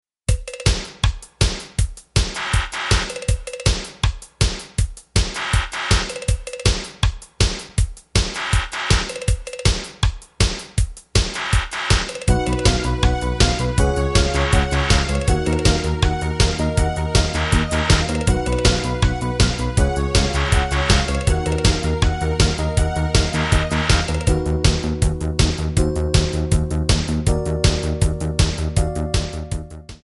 Eb
Backing track Karaoke
Pop, Disco, Musical/Film/TV, 1980s